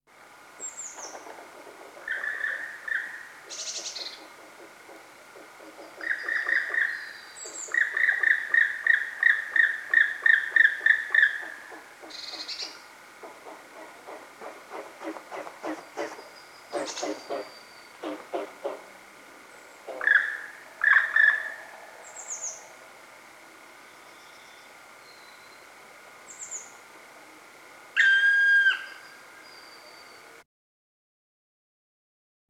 Schwarzspecht Ruf
Schwarzspecht-Ruf-Voegel-in-Europa.wav